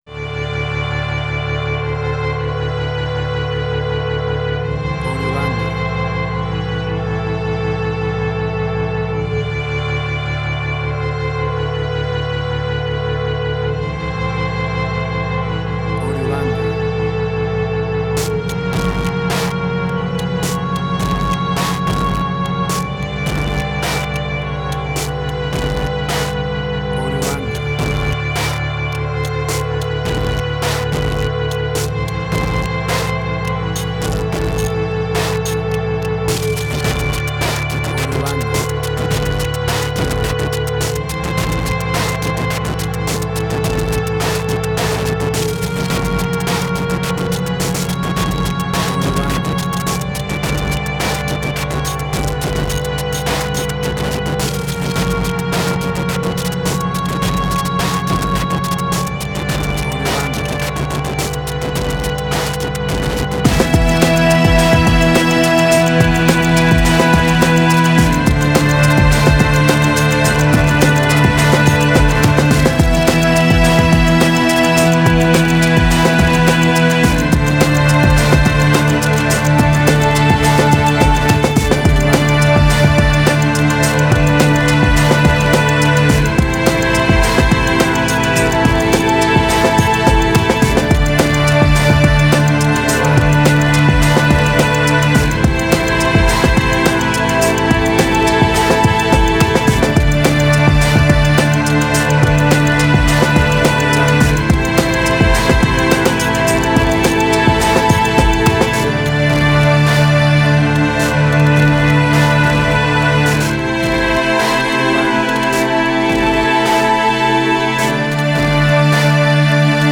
IDM, Glitch.
Tempo (BPM): 105